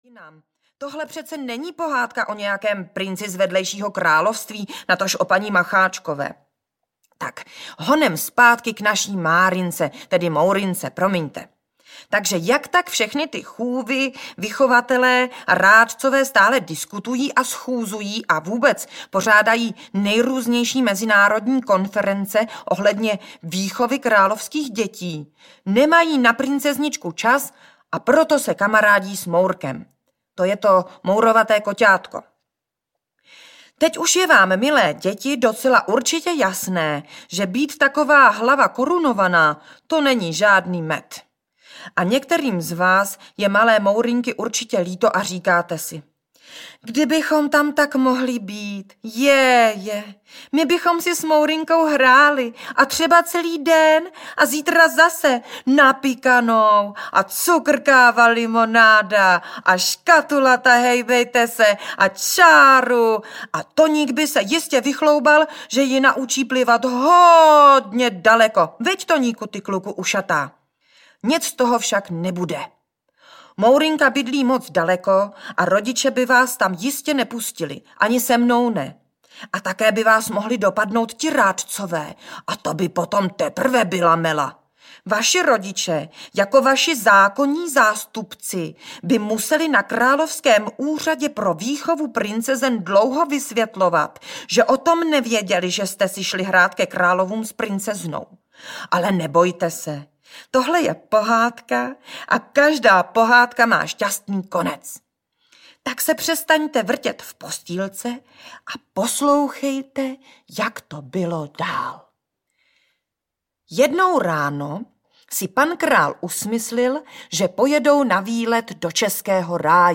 O Mourince a Lojzíkovi audiokniha
Audiokniha O Mourince a Lojzíkovi, kterou napsala Radůza, je audiokniha pohádek pro děti od známé hudebnice a zpěvačky.
Ukázka z knihy
• InterpretRadůza